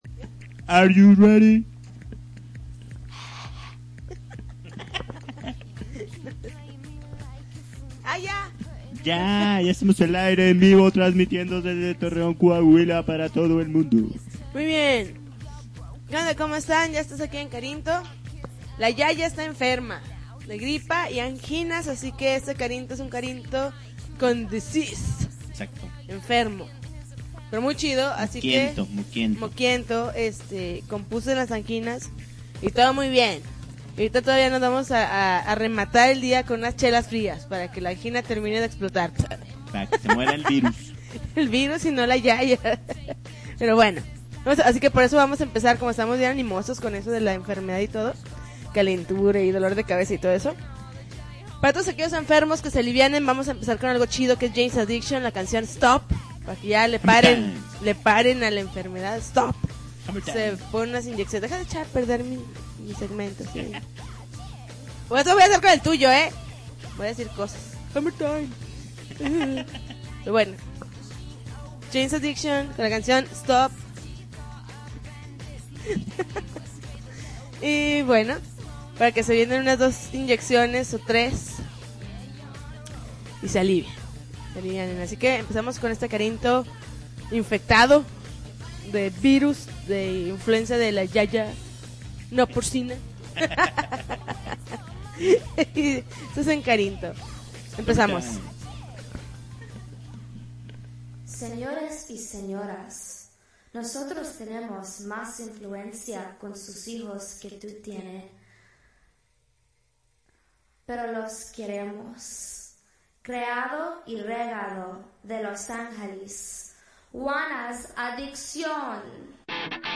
February 21, 2010Podcast, Punk Rock Alternativo